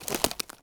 wood_tree_branch_move_10.wav